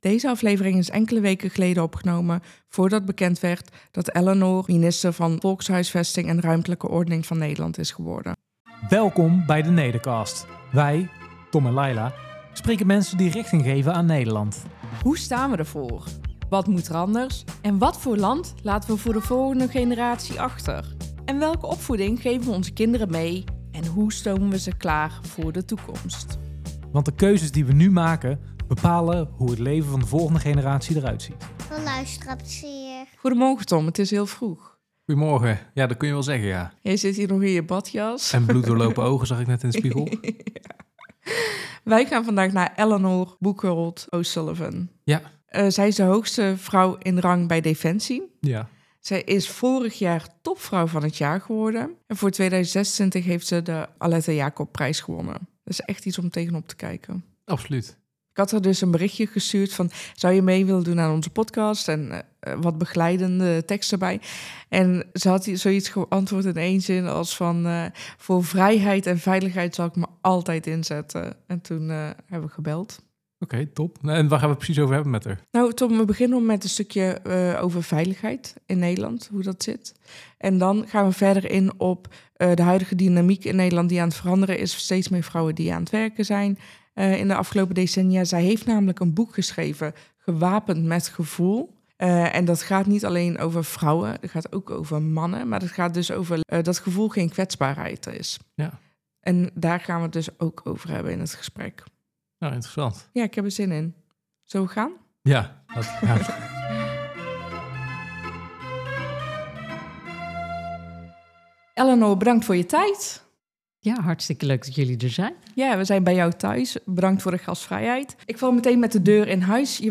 In dit eerste seizoen van tien aflevering, wordt er elke week een gastspreker uitgenodigd om het over een onderwerp gerelateerd aan Nederland te hebben. Denk bijvoorbeeld aan de Nederlandse cultuur, economie en onze grootste zorgen.